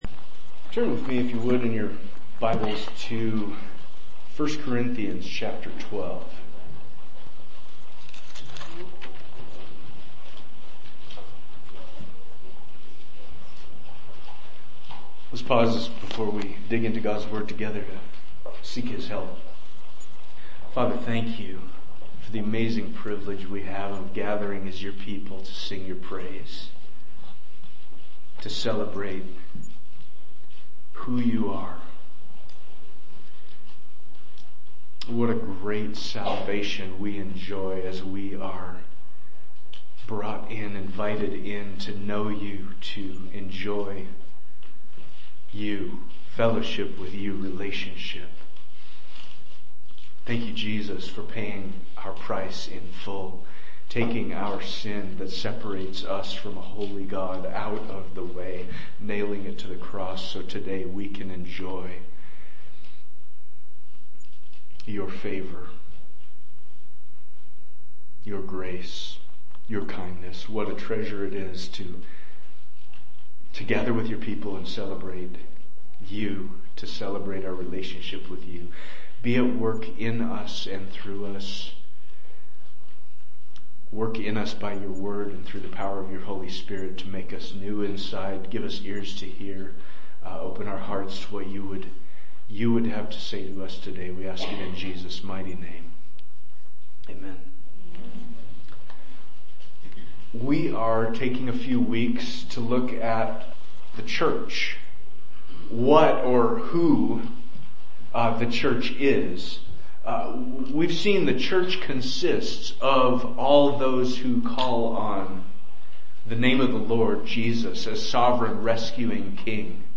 Preaching from the Pulpit of Ephraim Church of the Bible